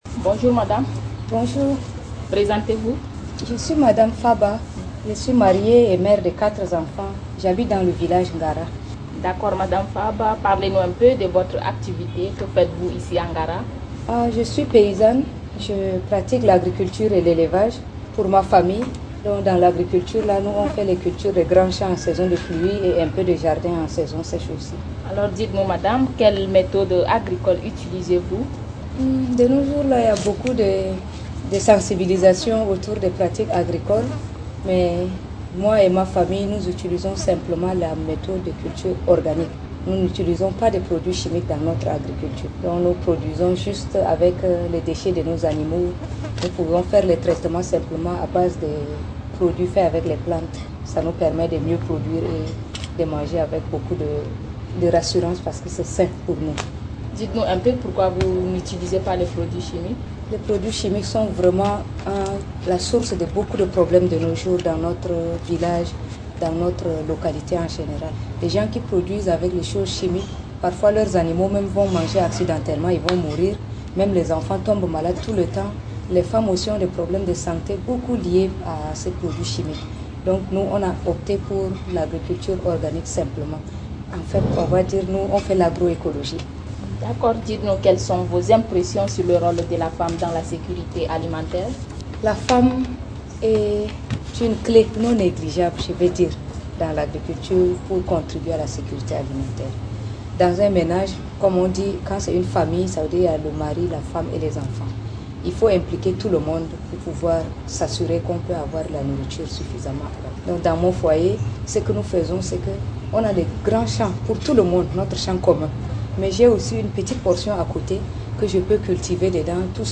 Témoignage : Le rôle des femmes dans la promotion de l’agroécologie et de la sécurité alimentaire